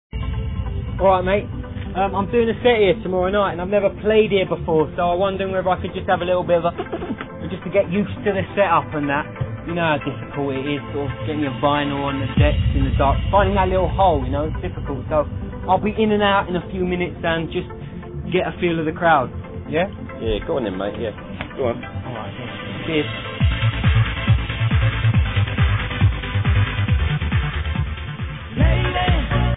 Its some other 1999/2000 style uplifter....